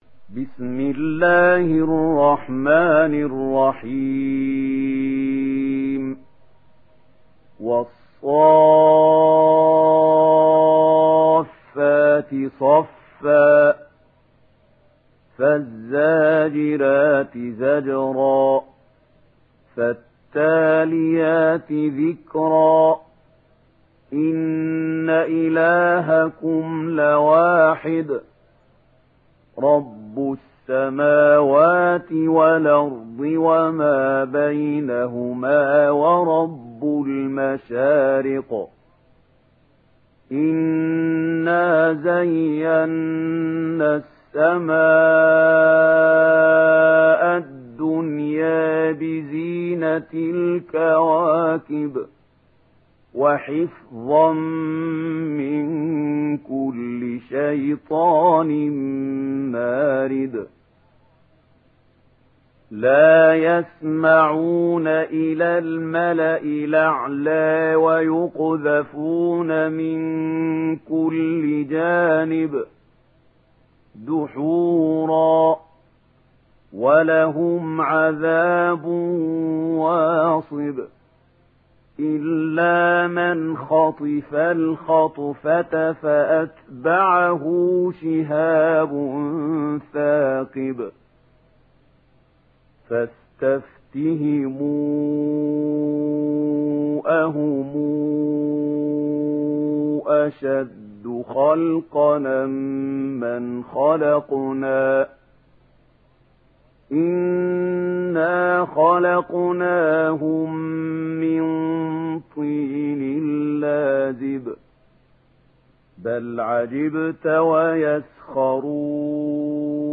সূরা আস-সাফ্‌ফাত ডাউনলোড mp3 Mahmoud Khalil Al Hussary উপন্যাস Warsh থেকে Nafi, ডাউনলোড করুন এবং কুরআন শুনুন mp3 সম্পূর্ণ সরাসরি লিঙ্ক